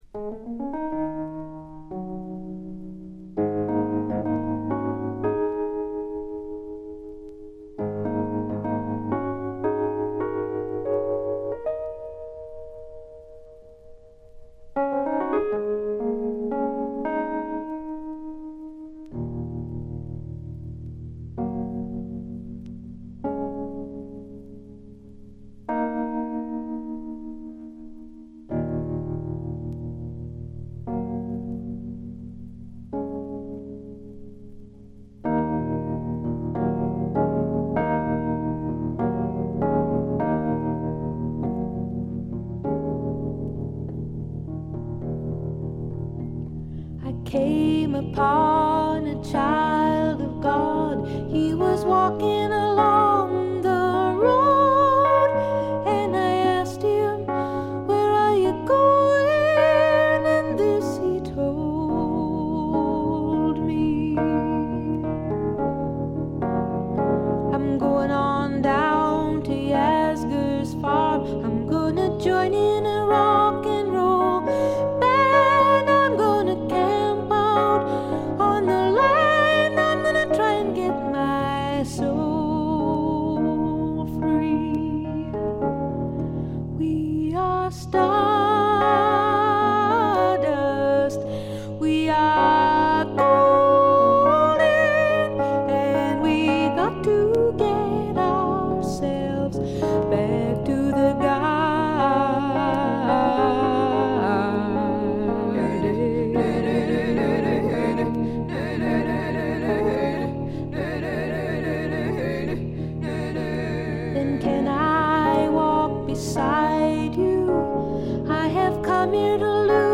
ほとんどノイズ感無し。
美しいことこの上ない女性シンガー・ソングライター名作。
フィメールフォーク好きなら絶対の必携作です。
試聴曲は現品からの取り込み音源です。
Recorded At - A&M Studios